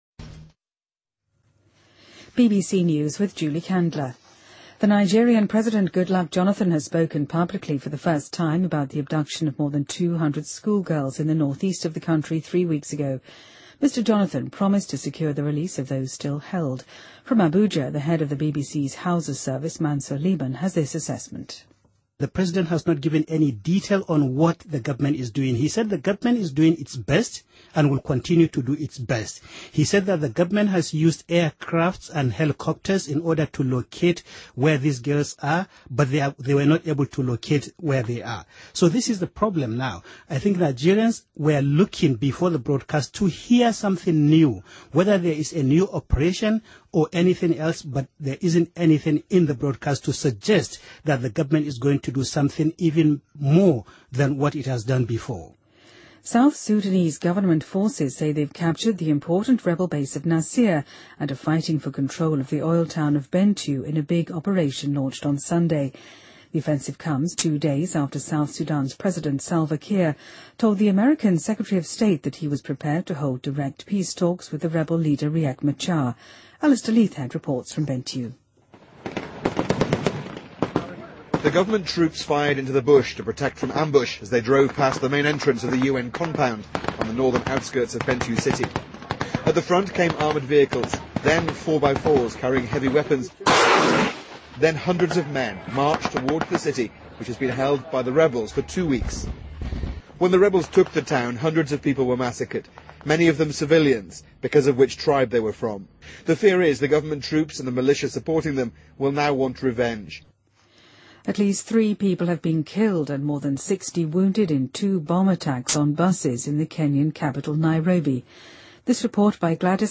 BBC news,9名表演者在美国罗德岛的马戏表演中严重受伤